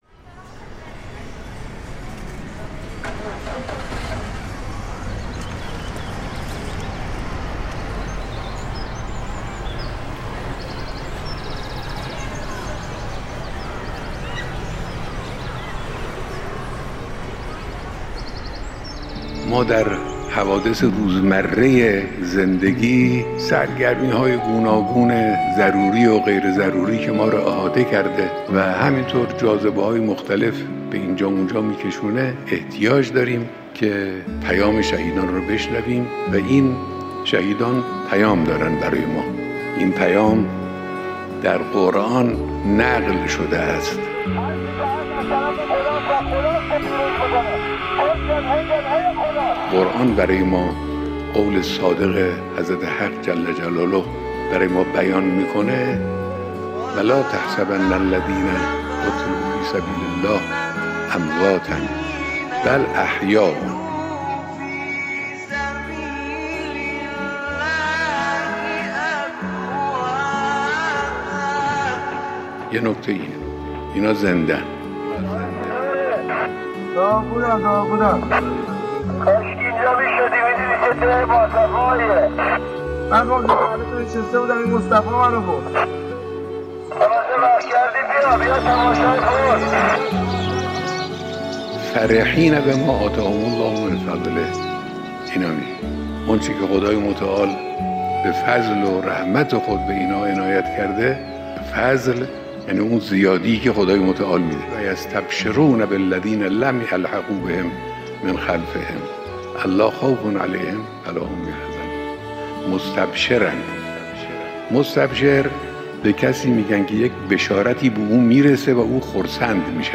پایگاه اطلاع‌رسانی دفتر حفظ و نشر آثار حضرت آیت‌الله خامنه‌ای در کلیپی بخشی از بیانات معظم‌له را در رابطه با پیامی که شهیدان طبق آیات ۱۶۹ و ۱۷۰ سوره آل عمران برای مردم دارند، منتشر کرد.
در این کلیپ جملاتی چند هم از شهید آوینی آمده است: